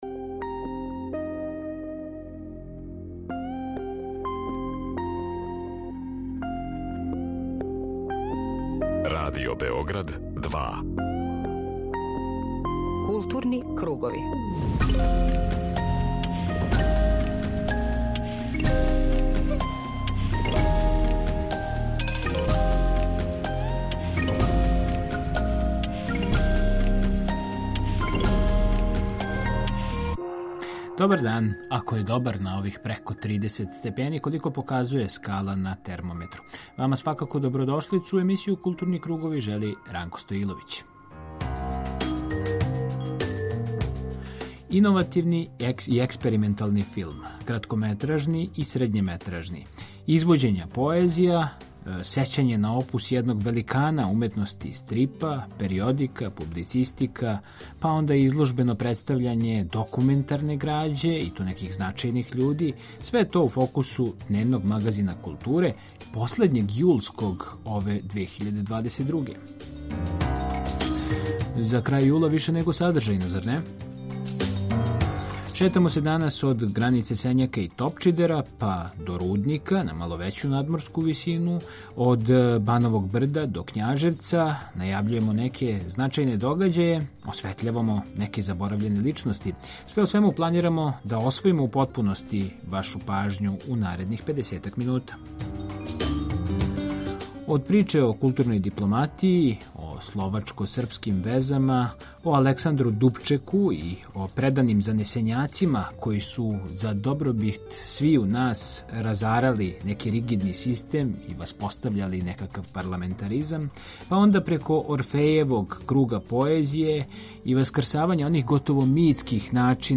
Дневни магазин културе Из садржаја емисије издвајамо: преузми : 19.80 MB Културни кругови Autor: Група аутора Централна културно-уметничка емисија Радио Београда 2.